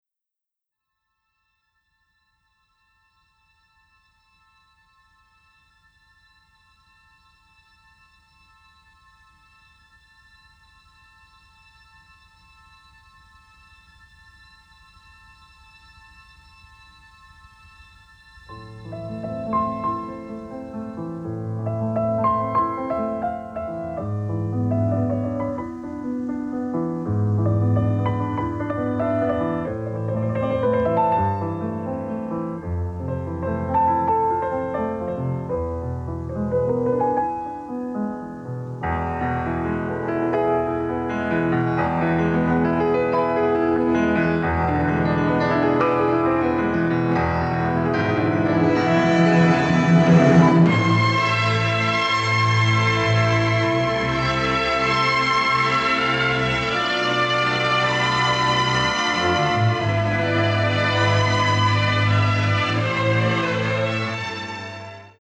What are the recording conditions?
and the resulting sound quality is very much improved.